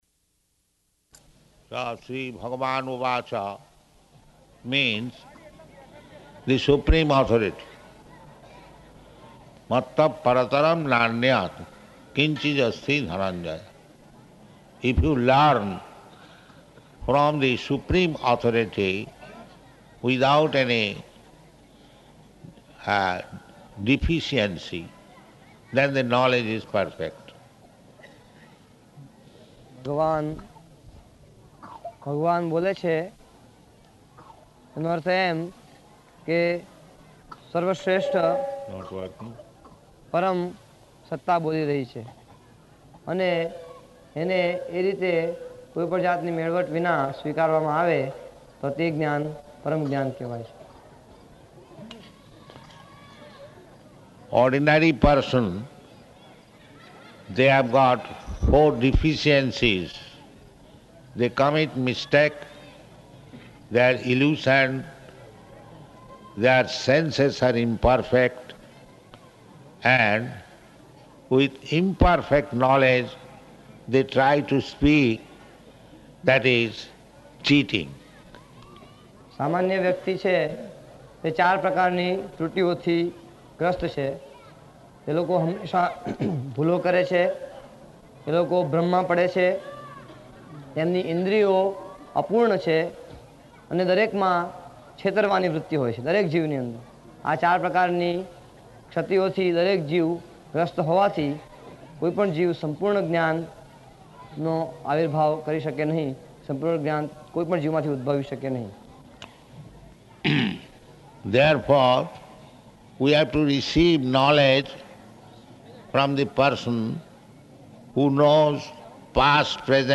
Location: Sanand
[translated throughout]